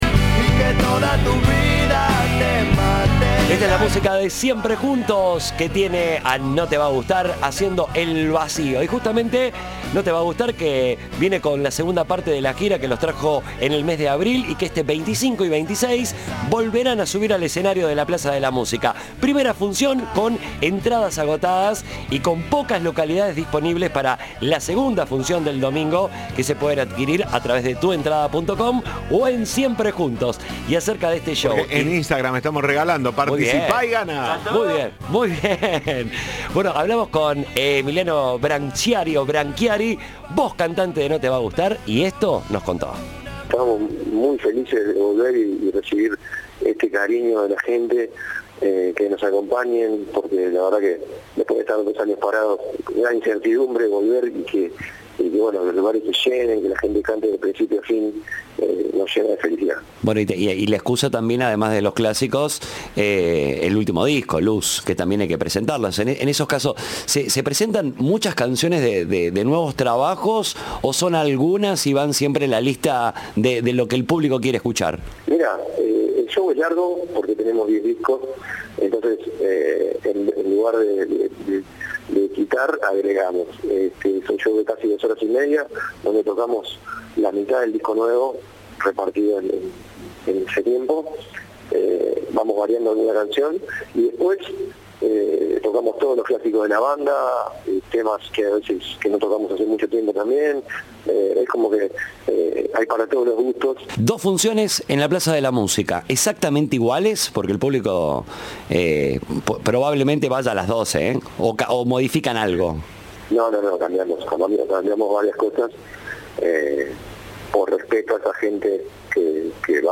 La banda festejará un nuevo aniversario en la Plaza de la Música este 25 y 26 de junio. Su cantante y emblema, Emiliano Brancciari, contó a Cadena 3 cómo serán los shows que darán en "La Docta".